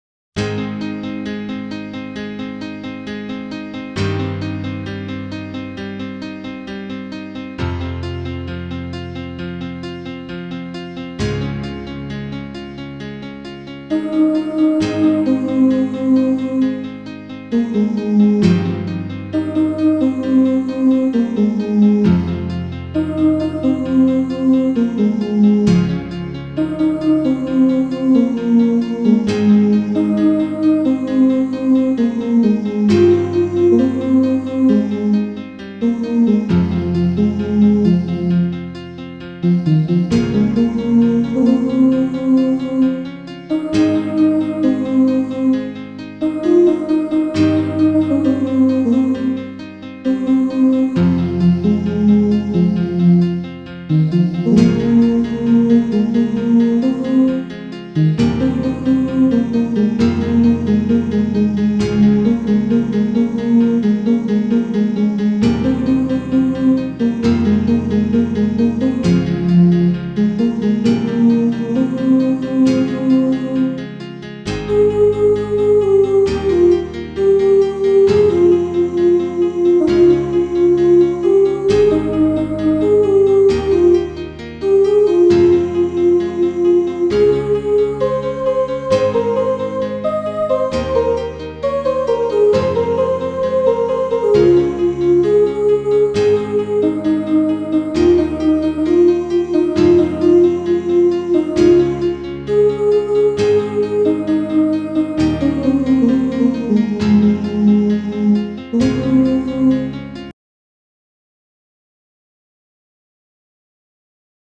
Bass (low voice) Track